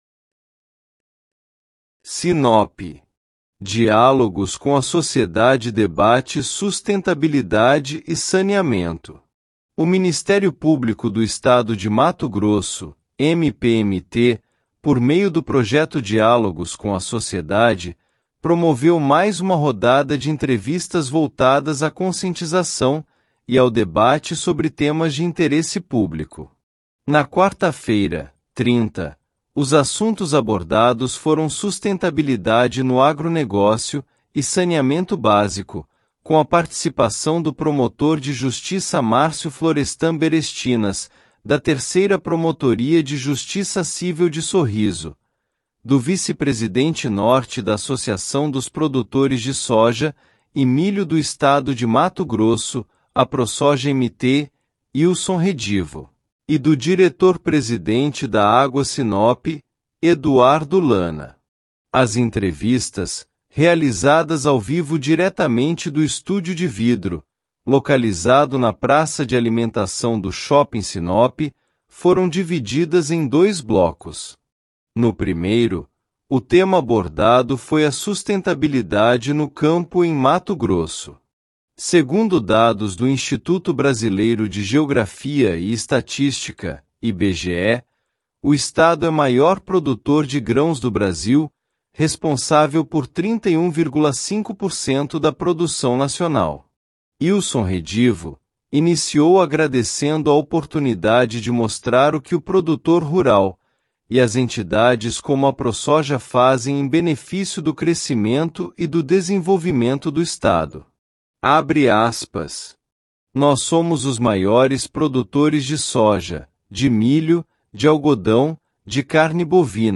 As entrevistas, realizadas ao vivo diretamente do estúdio de vidro localizado na Praça de Alimentação do Shopping Sinop, foram divididas em dois blocos. No primeiro, o tema abordado foi a sustentabilidade no campo em Mato Grosso.